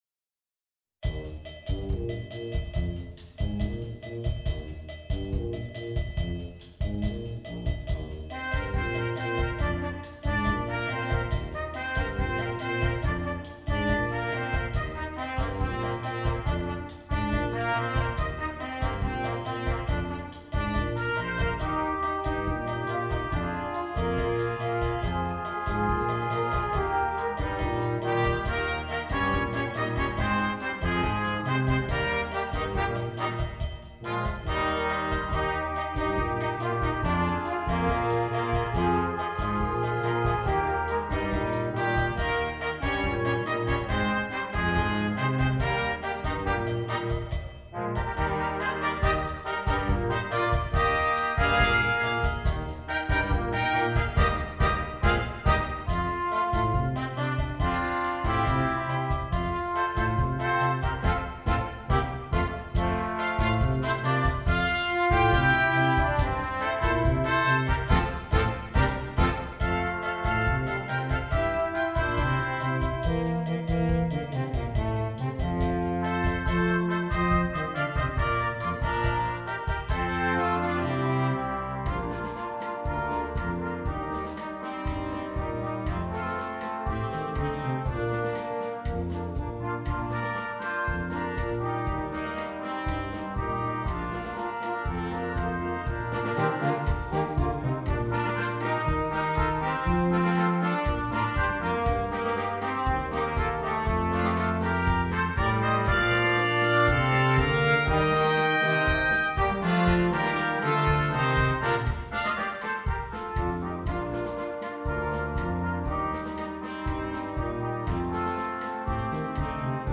Brass Quintet - optional Percussion